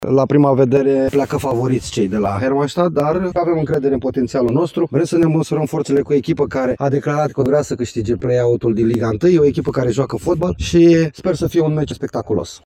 „Principalul” rosso-nerrilor bănățeni, Flavius Stoican, vorbește despre încredere: